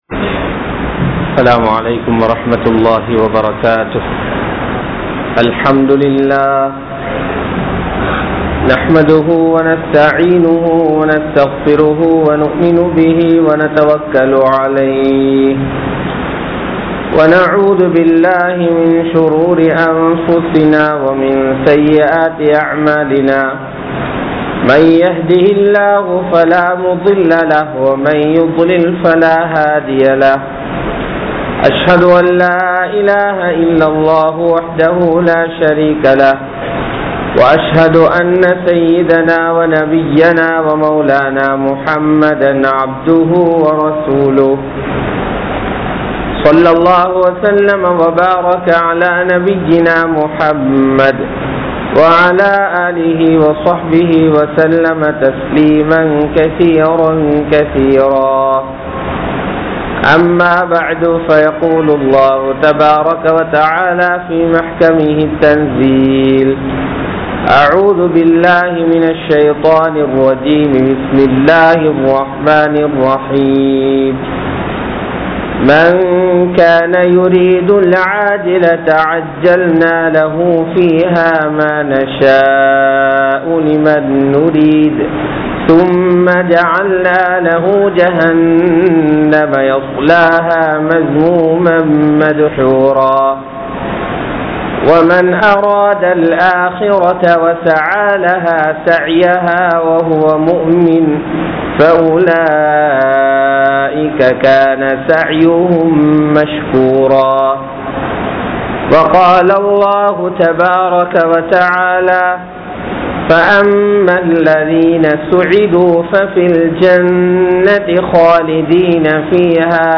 Iv Ulaham Soathanaiyaanathu (இவ்வுலகம் சோதனையானது) | Audio Bayans | All Ceylon Muslim Youth Community | Addalaichenai
Thaqwa Jumua Masjith